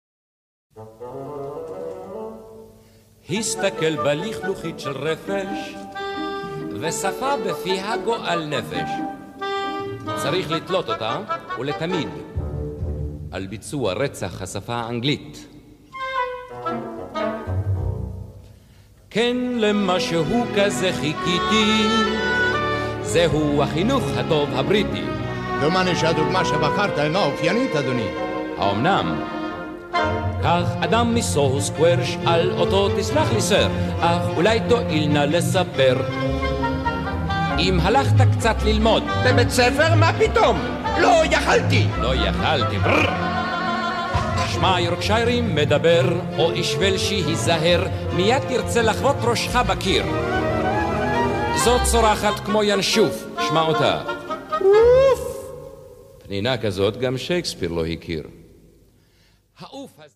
Hebrew Cast Recording 17